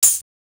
Index of dough-samples/ tidal-drum-machines/ machines/ LinnLM1/ linnlm1-tb/
LM-1_TAMB_TL.wav